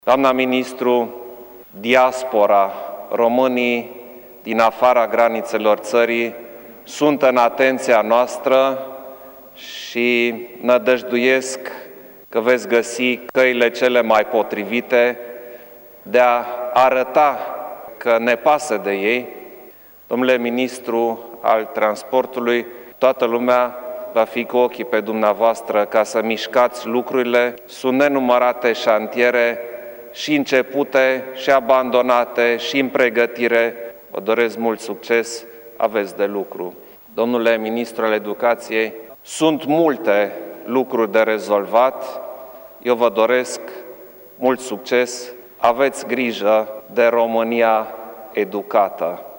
După depunerea jurămintelor, șeful statului le-a transmis noilor miniștri câte un mesaj, încercând să scoată în evidență problemele cu care se confruntă fiecare domeniu în parte:
iohannis-investitura.mp3